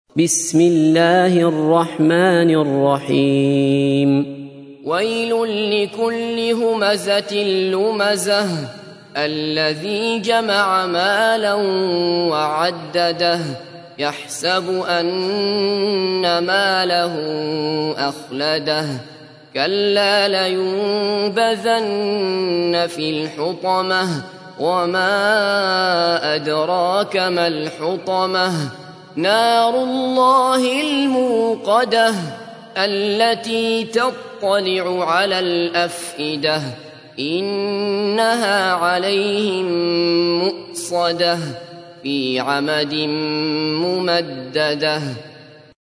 تحميل : 104. سورة الهمزة / القارئ عبد الله بصفر / القرآن الكريم / موقع يا حسين